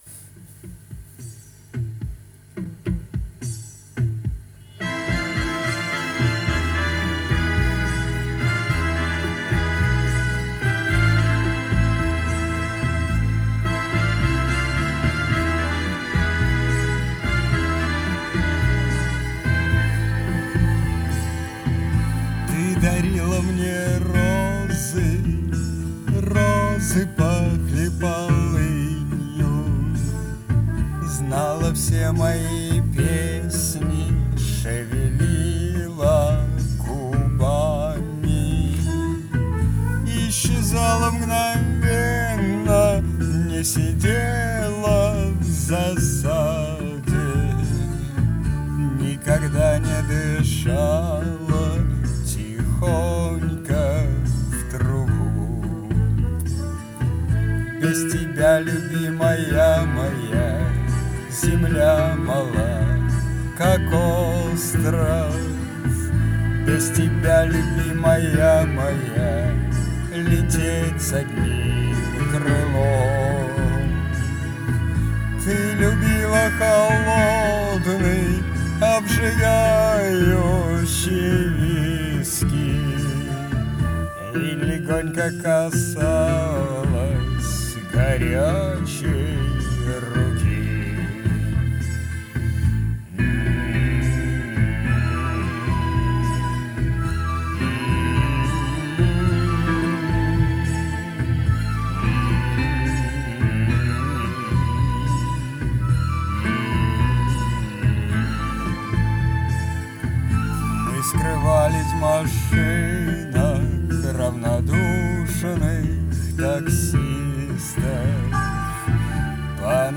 Чистой воды экспромт)